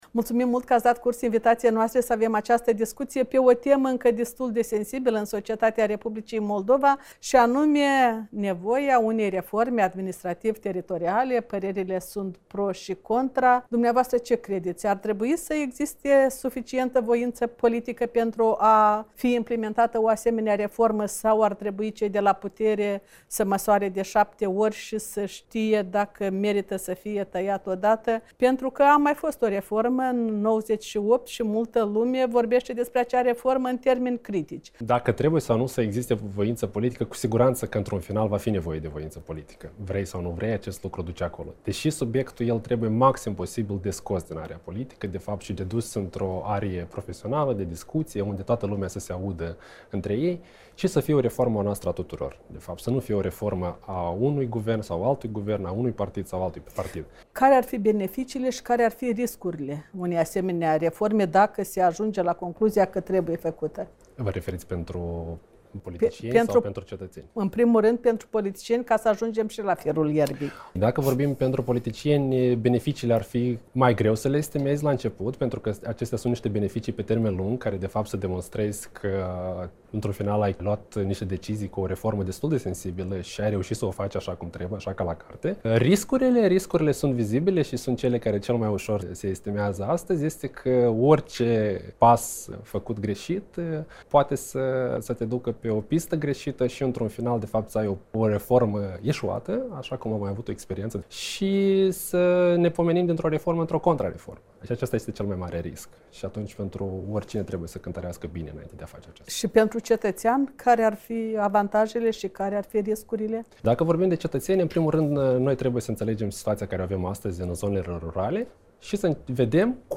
O convorbire